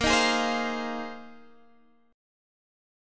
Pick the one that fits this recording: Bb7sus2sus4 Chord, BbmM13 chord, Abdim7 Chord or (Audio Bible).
Bb7sus2sus4 Chord